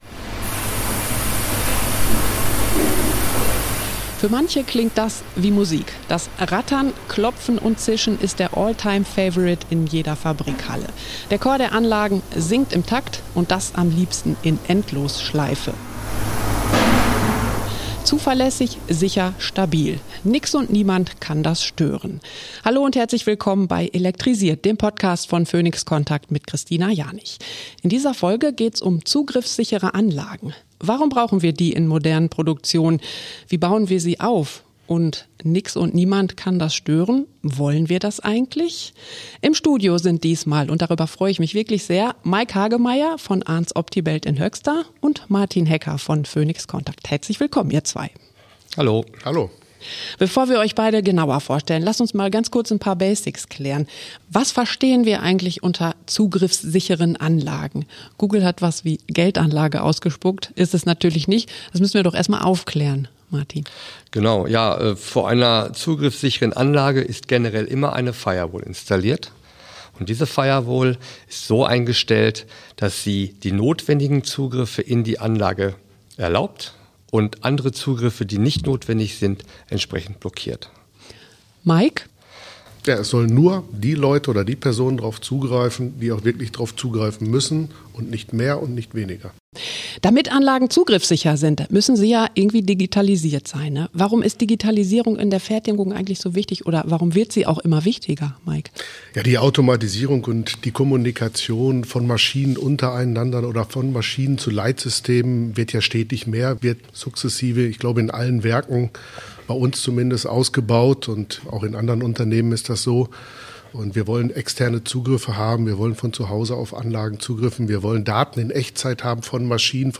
Eine Folge über Verfügbarkeit, Sicherheit und Fernwartung in globalen Produktionsnetzwerken. Es klingt beruhigend: das gleichmäßige Rattern, Klopfen und Zischen von Maschinen und Anlagen.